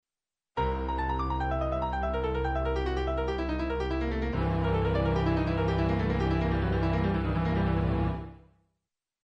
分散和音のモチーフも　(分散和音の解説にこだわるなら）２２小節目からリズムを変えてチェロとピアノが交互にあらわれる。
このあとにあらわれるピアノのパッセージはかなり技巧的で華麗である。